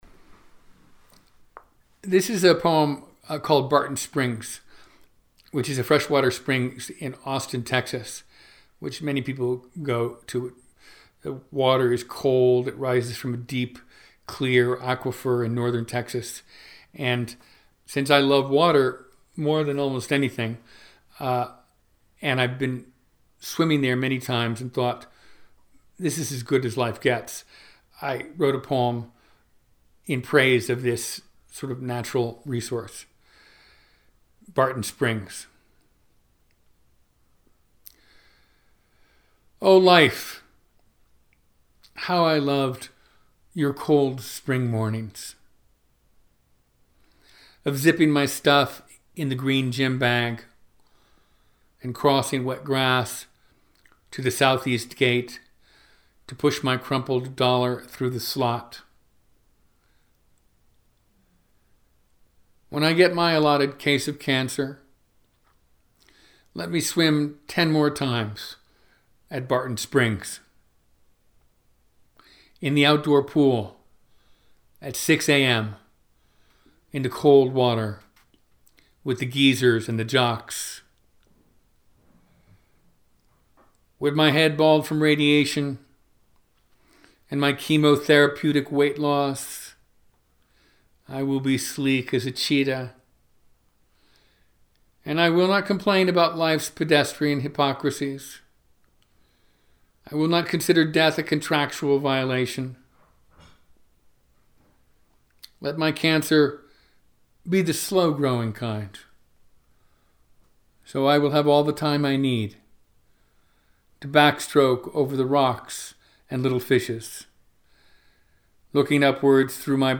In the summer of 2018 Tony recorded himself reading twenty of those poems.